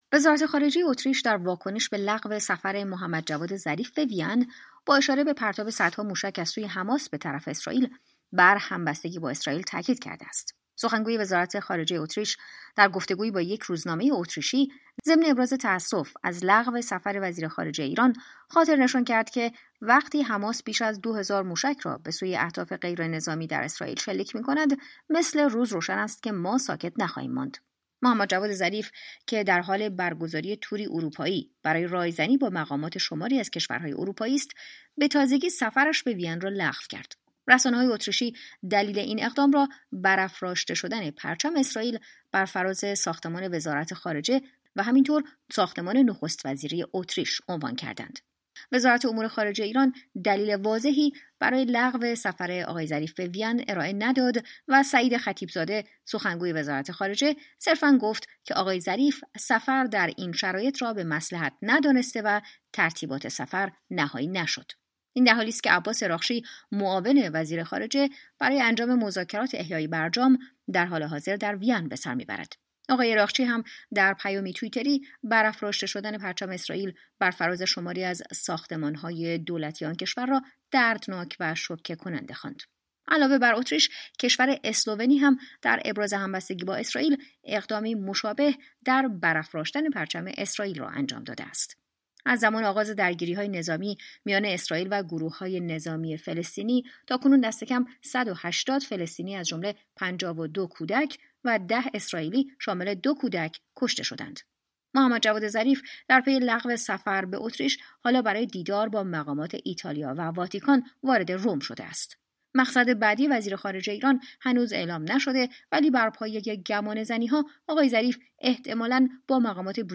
پس از آنکه محمد جواد ظریف در جریان تور اروپایی اش، سفر به پایتخت اتریش را لغو کرد، وزارت امور خارجه اتریش از این اقدام ابراز تاسف کرده ولی همچنان بر ابراز همبستگی با اسرائیل تاکید کرد. جزییات بیشتر در گزارشی